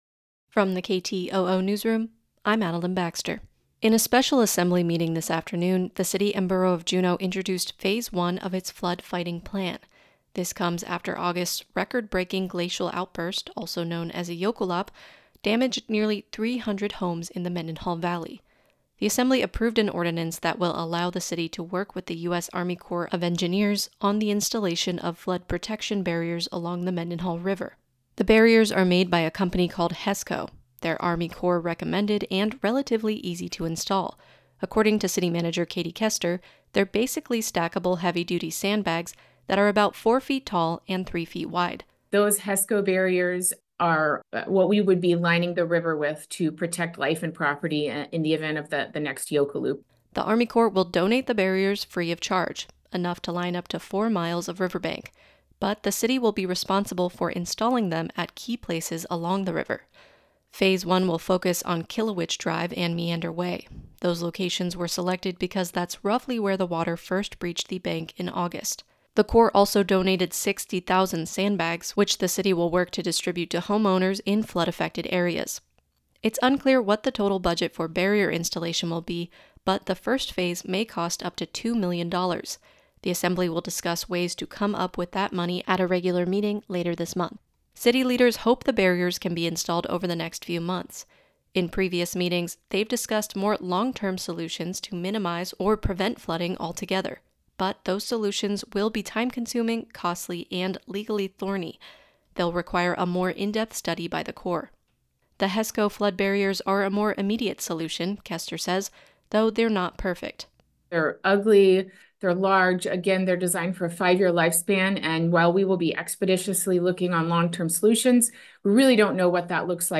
Newscast – Thursday, Oct. 3, 2024